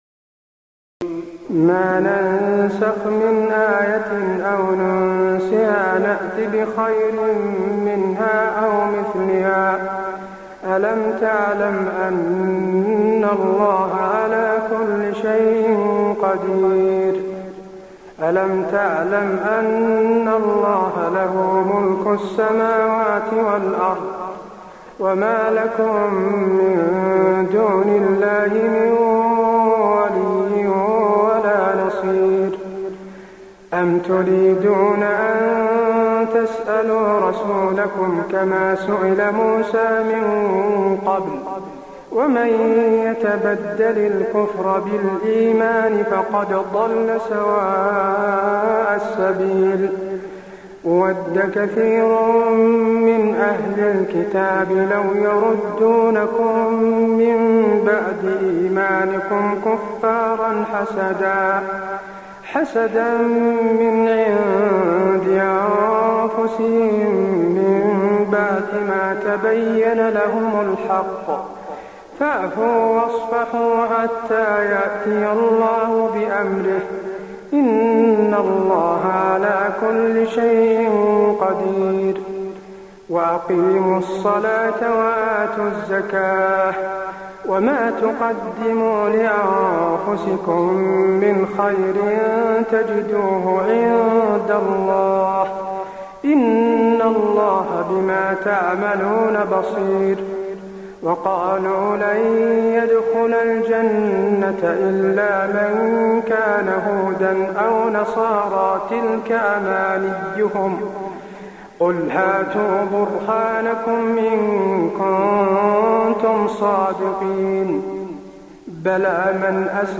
تراويح الليلة الأولى رمضان 1423هـ من سورة البقرة (106-141) Taraweeh 1st night Ramadan 1423H from Surah Al-Baqara > تراويح الحرم النبوي عام 1423 🕌 > التراويح - تلاوات الحرمين